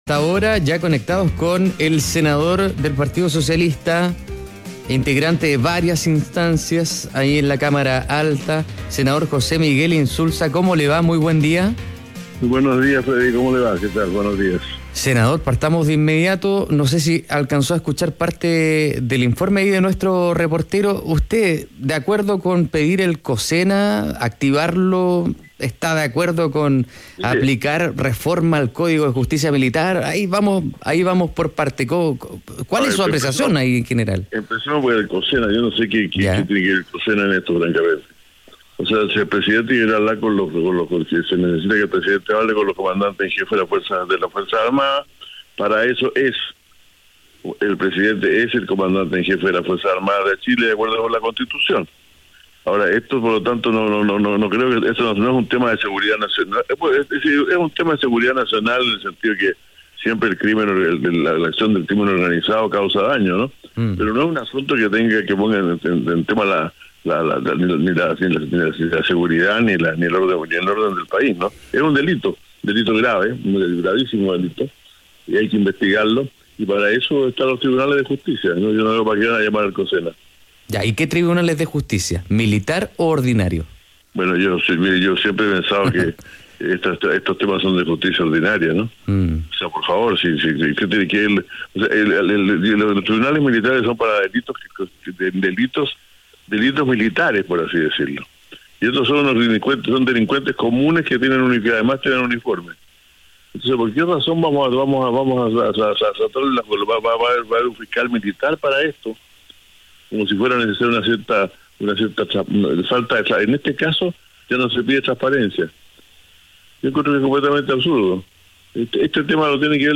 Radio ADN - Entrevista al senador José Miguel Insulza (PS)